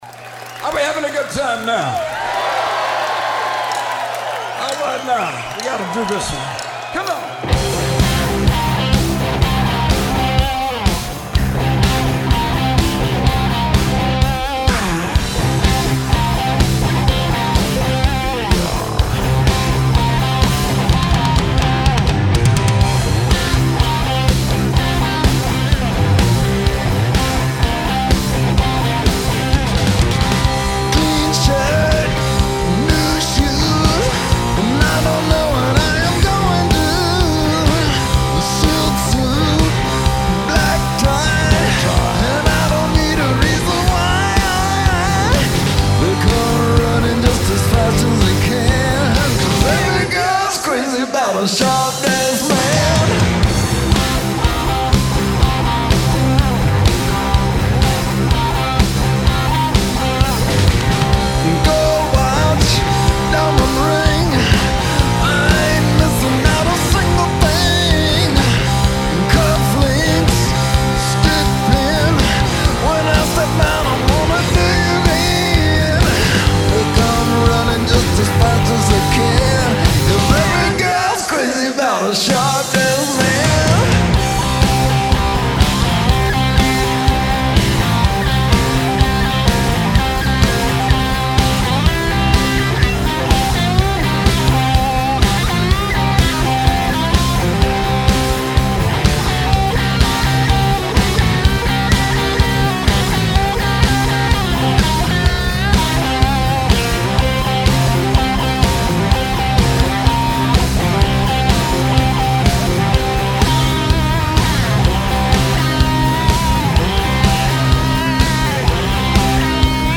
in concert in the City of Angels